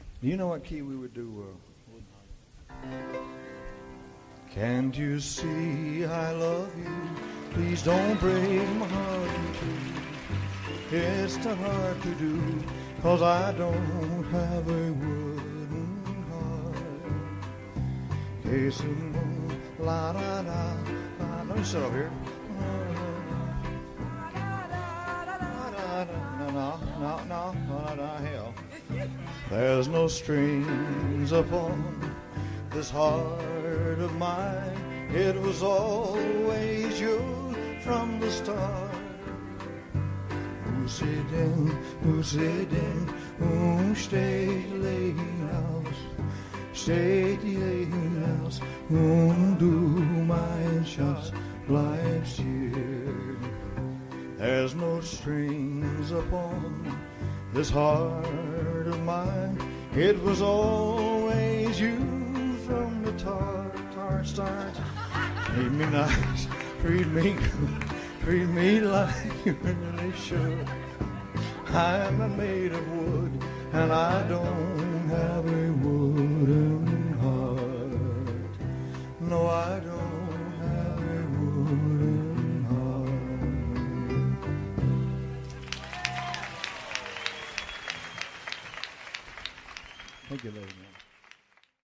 Live 13th December 1975 Las Vegas